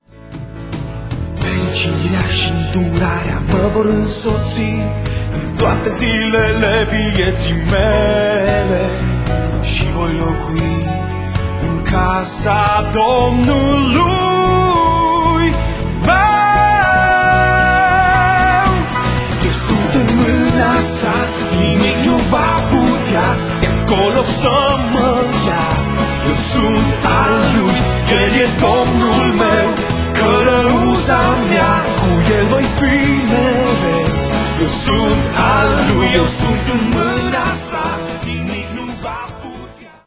Abordand stilul pop-rock intr-o maniera proprie